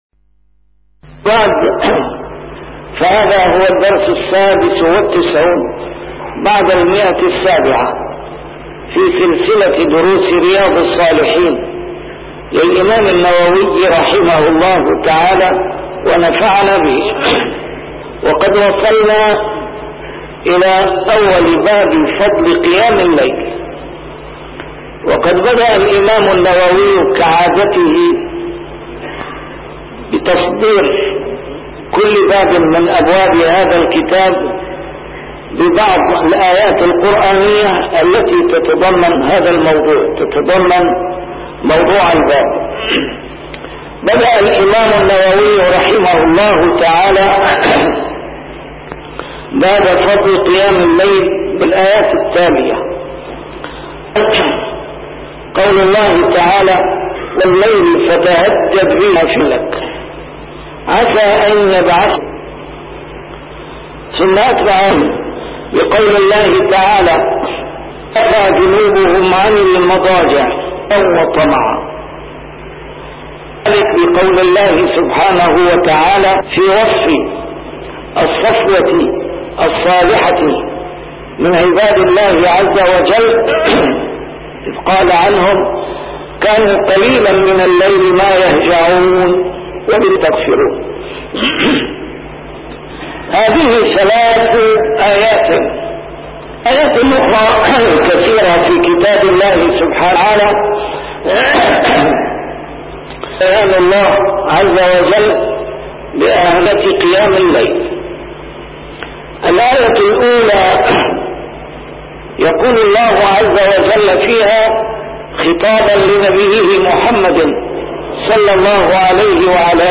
A MARTYR SCHOLAR: IMAM MUHAMMAD SAEED RAMADAN AL-BOUTI - الدروس العلمية - شرح كتاب رياض الصالحين - 796- شرح رياض الصالحين: فضل قيام الليل